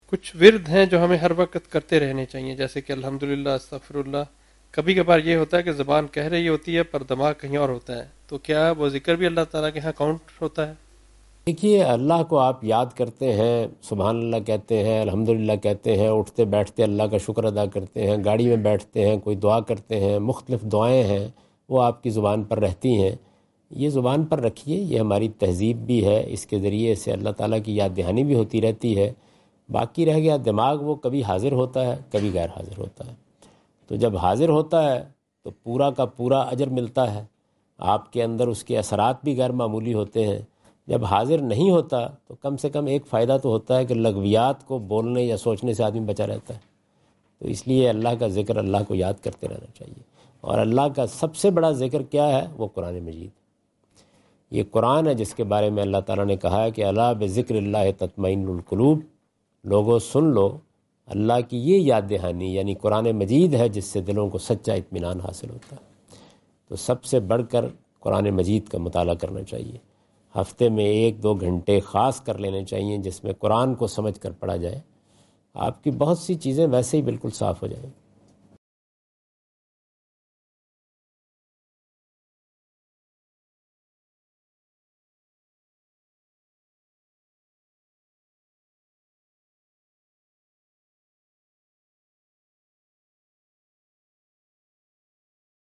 Javed Ahmad Ghamidi answer the question about "Saying Words of Glorification Without Concentration" during his Australia visit on 11th October 2015.
جاوید احمد غامدی اپنے دورہ آسٹریلیا کے دوران ایڈیلیڈ میں "بے دھیانی میں اللہ کا ذکر کرتے جانا" سے متعلق ایک سوال کا جواب دے رہے ہیں۔